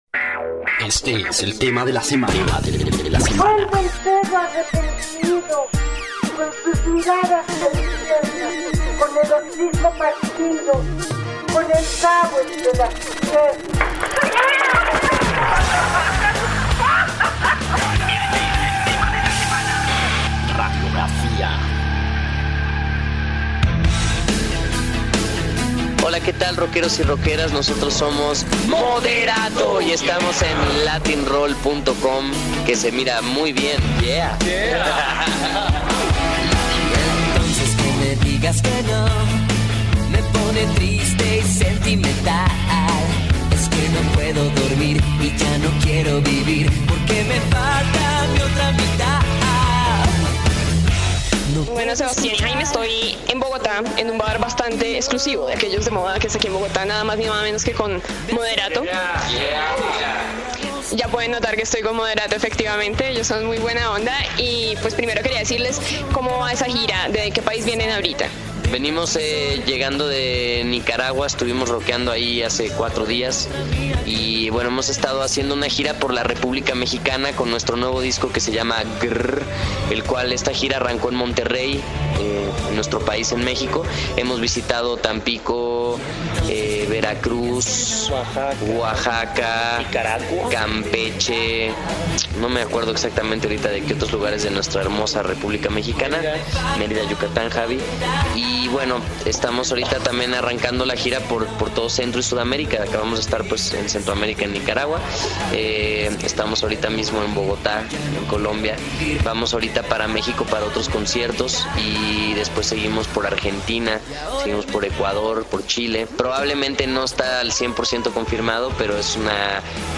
Latin-Roll - Entrevistas Moderatto Reproducir episodio Pausar episodio Mute/Unmute Episode Rebobinar 10 segundos 1x Fast Forward 30 seconds 00:00 / 25:04 Suscribir Compartir Feed RSS Compartir Enlace Incrustar